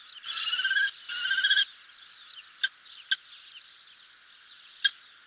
FALCO TINNUNCULUS - KESTREL - GHEPPIO
A male Kestrel approached the nest in flight, where the female was incubating the eggs. - POSITION: Poponaio farm near Grosseto town, LAT.N 42°47'/LONG.E 11°06'- ALTITUDE: 12 m. - VOCALIZATION TYPE: calls between male and female near the nest.
Background: sparrow calls.